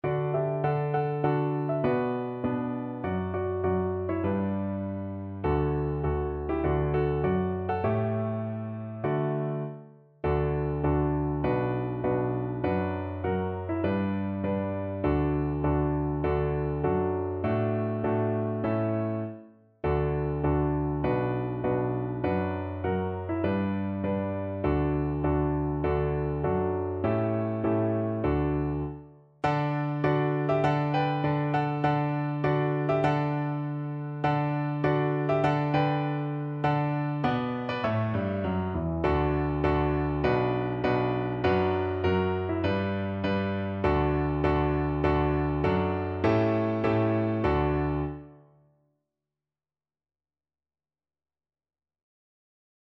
Play (or use space bar on your keyboard) Pause Music Playalong - Piano Accompaniment Playalong Band Accompaniment not yet available reset tempo print settings full screen
D major (Sounding Pitch) (View more D major Music for Viola )
4/4 (View more 4/4 Music)
Moderato